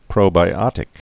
(prōbī-ŏtĭk)